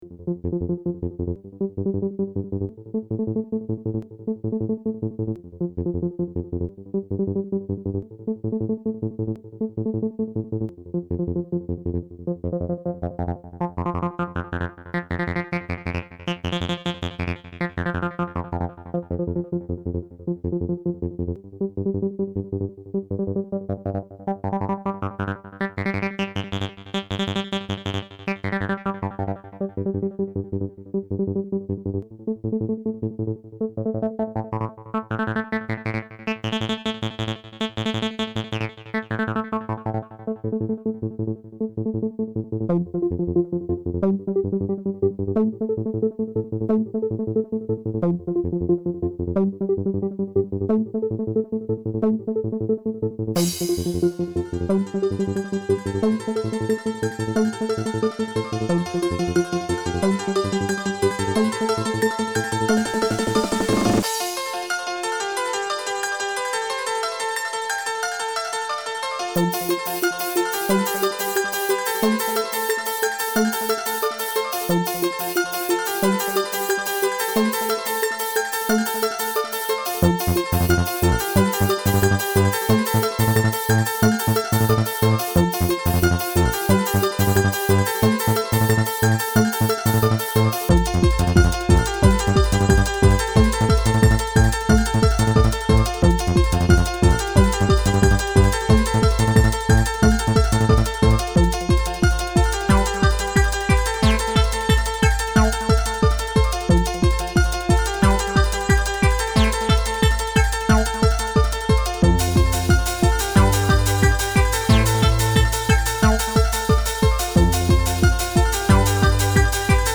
dance remix
• Jakość: 44kHz, Mono